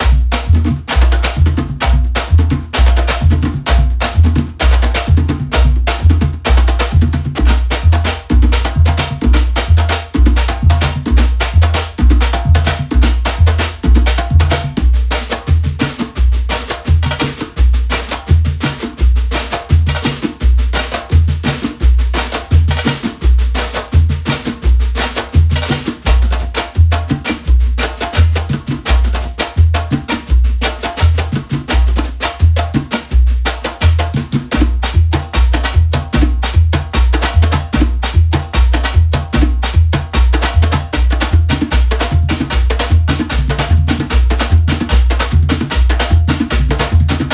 Percussão Axé Music
Loops e one-shots versáteis que traduzem a vibração e a alegria do Axé, ideais para criar faixas com a cara do verão.
Loops Gerais, solos de instrumentos como Malacaxeta, Repique, Surdo e Timbal.
AXE-MUSIC-MASTER.wav